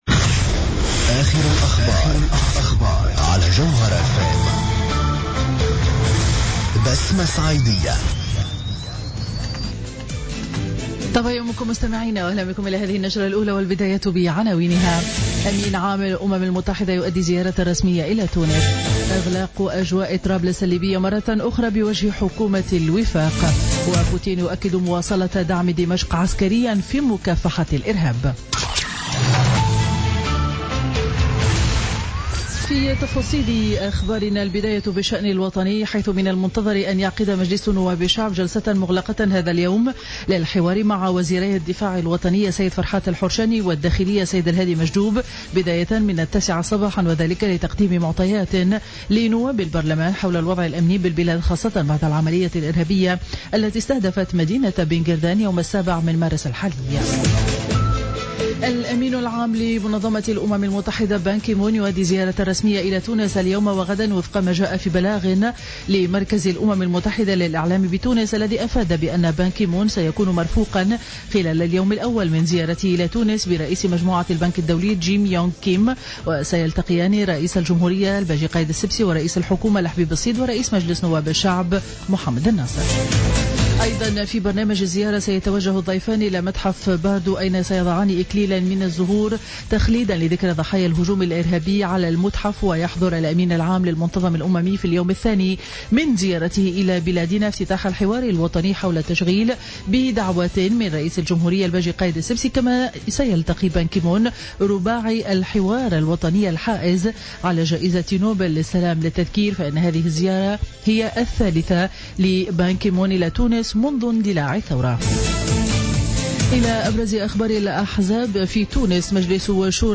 نشرة أخبار السابعة صباحا ليوم الاثنين 28 مارس 2016